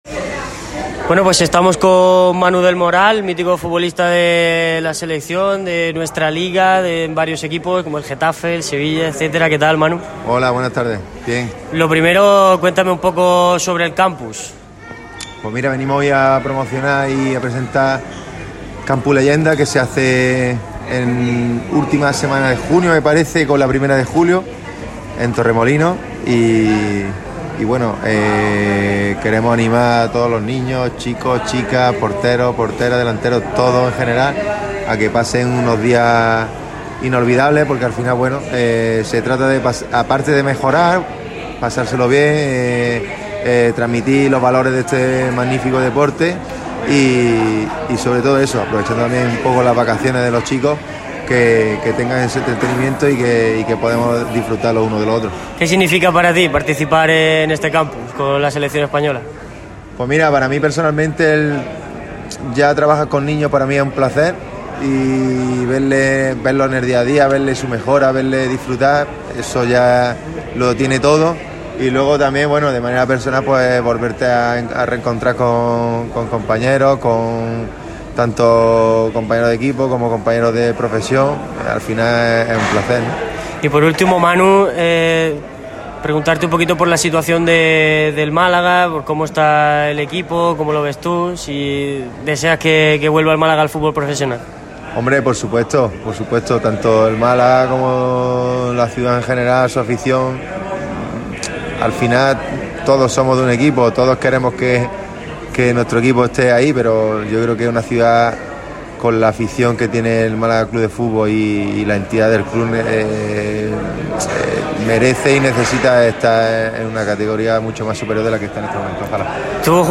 Radio MARCA Málaga ha tenido el placer de entrevistar a varios de los protagonistas, que han dejado algunas pinceladas sobre el Málaga CF. Las leyendas de la selección, se mojan sobre el Málaga CF.